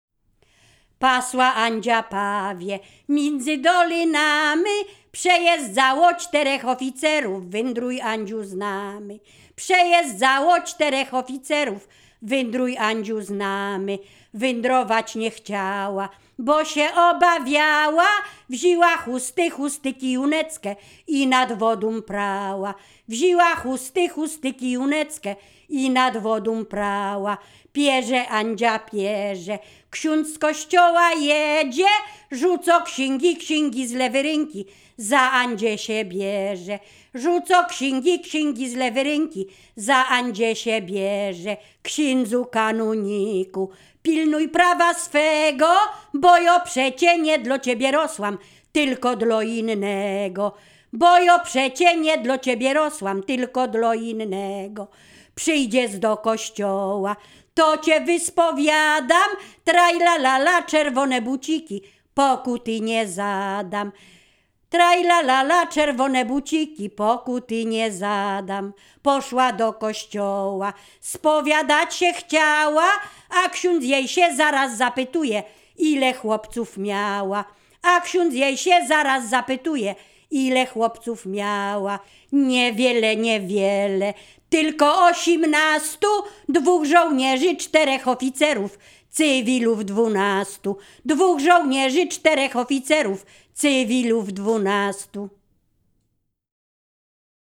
Ziemia Radomska
liryczne miłosne żartobliwe pieśni piękne